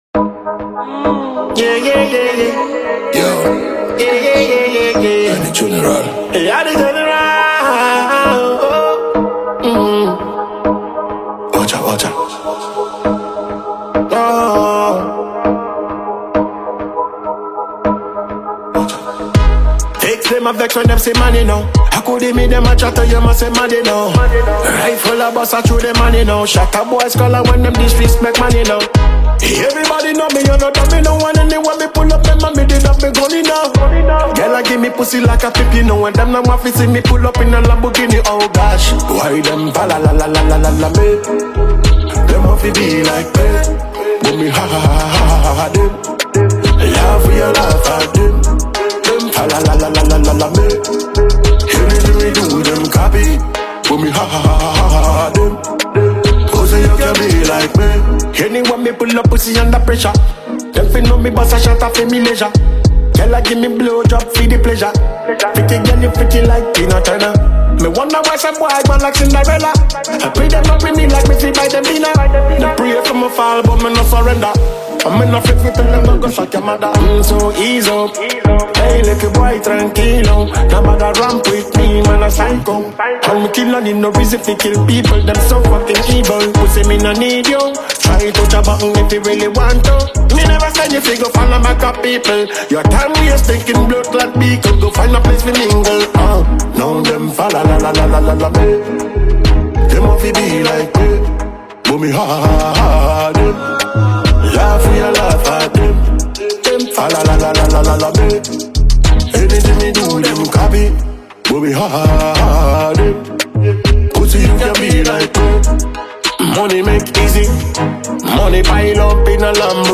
• Genre: Dancehall / Afrobeat